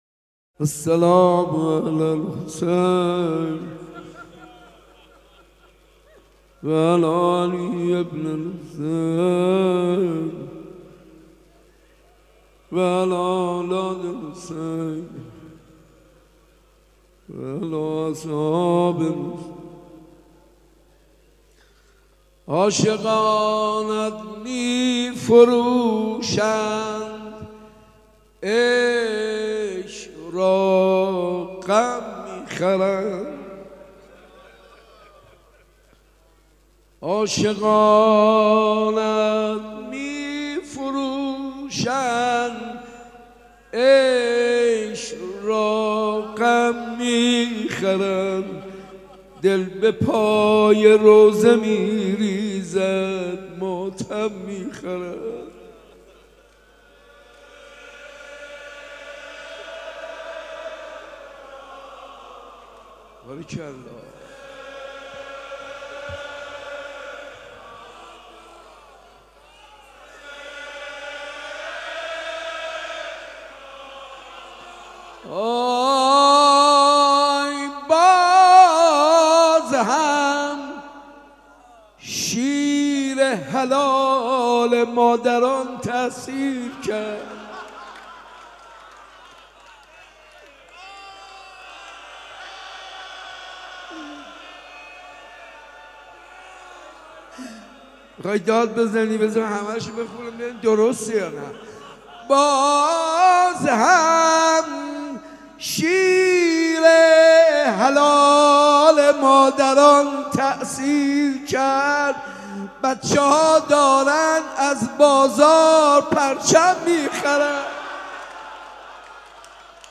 حاج منصور ارضی.mp3 | دانلود فایل حاج ابوالفضل بختیاری.mp3 | دانلود فایل موضوعات: دانلود مداحی برچسب ها: صوت , شب دوم ماه رمضان , حاج منصور ارضی
حاج منصور ارضی- شب اول محرم-مسجد ارگ.mp3